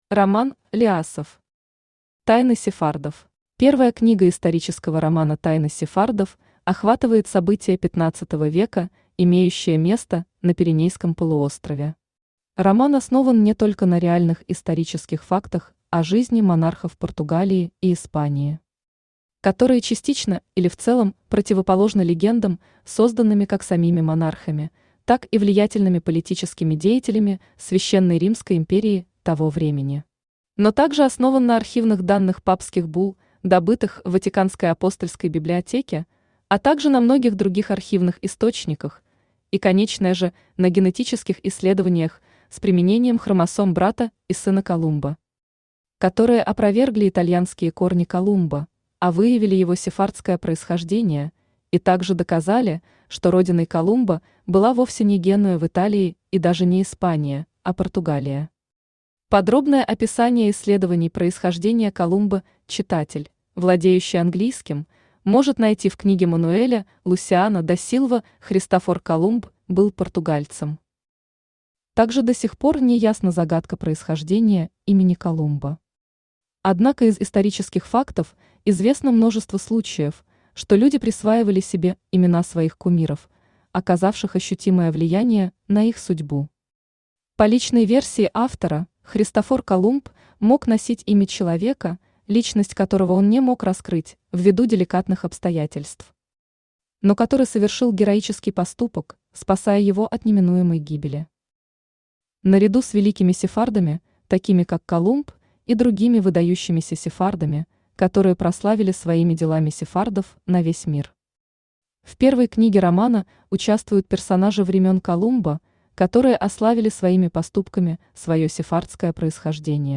Аудиокнига Тайны Сефардов | Библиотека аудиокниг